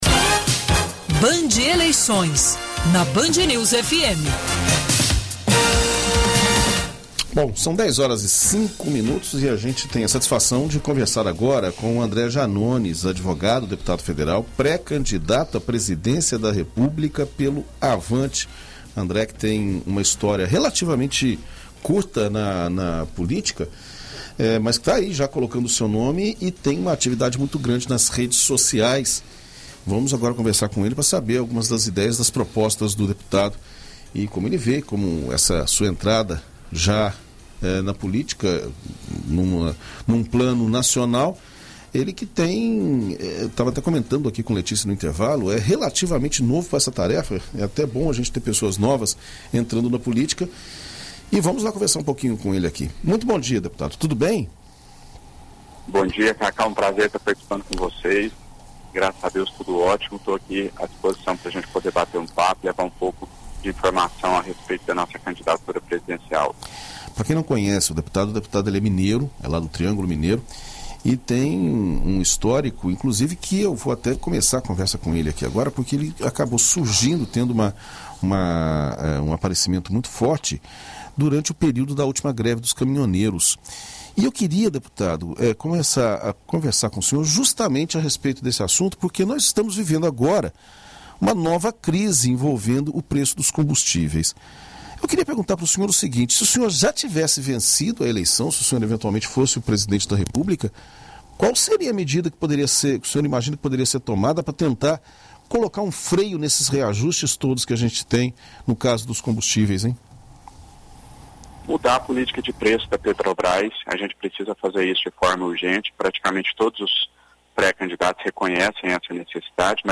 Em entrevista à BandNews FM Espírito Santo nesta sexta-feira (11), André Janones fala sobre as principais propostas de campanha, em especial às relacionadas com as políticas para os combustíveis no país.